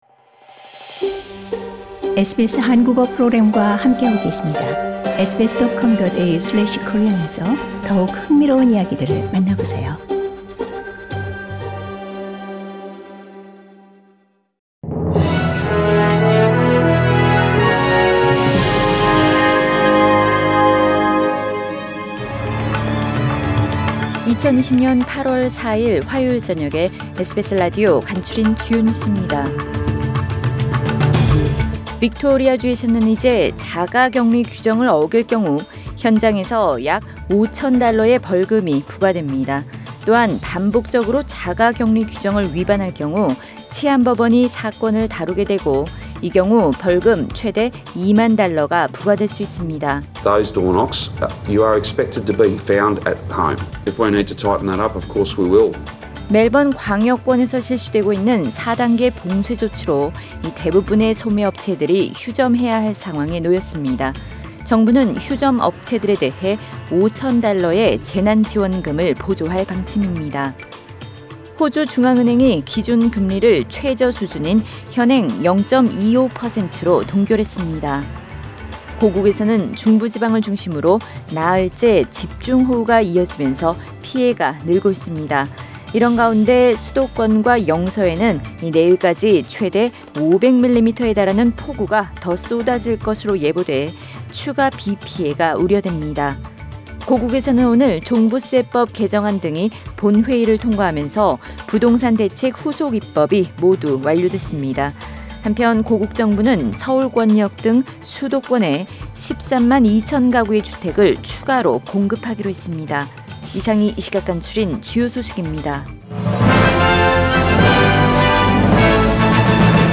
SBS 한국어 뉴스 간추린 주요 소식 – 8월 4일 화요일
2020년 8월 4일 화요일 저녁의 SBS Radio 한국어 뉴스 간추린 주요 소식을 팟 캐스트를 통해 접하시기 바랍니다.